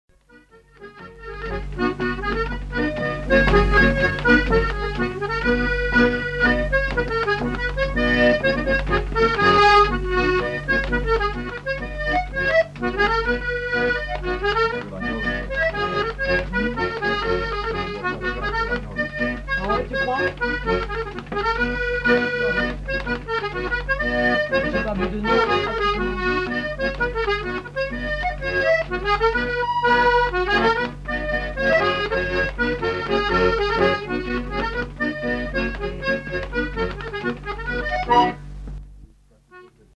Aire culturelle : Savès
Genre : morceau instrumental
Instrument de musique : accordéon diatonique
Danse : quadrille